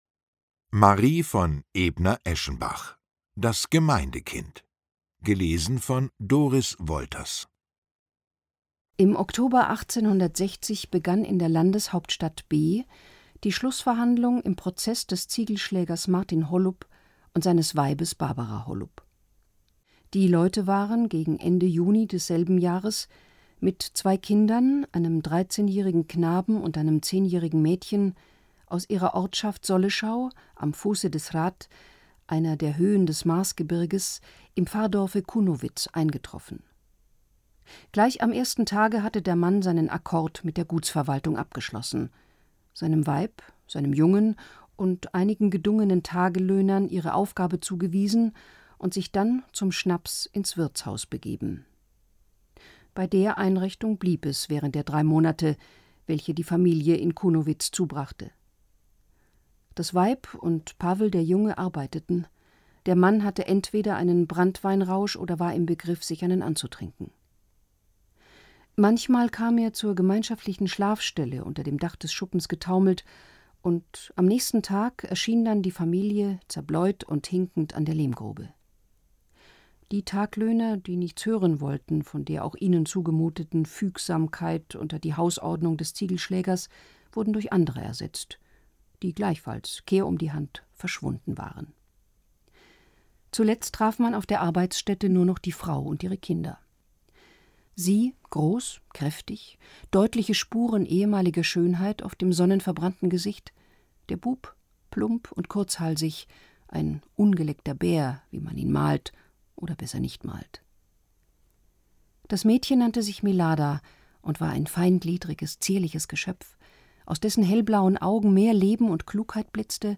Themenwelt Literatur Klassiker / Moderne Klassiker